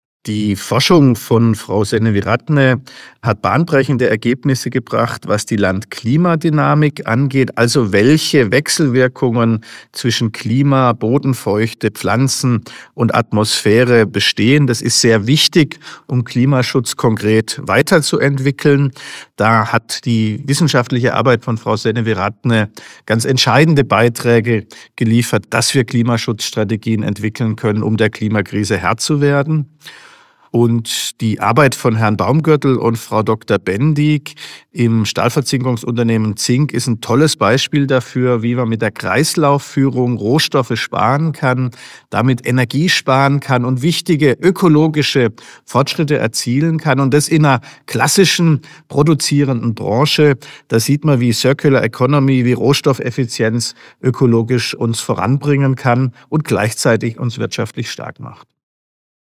Frage 2 an Alexander Bonde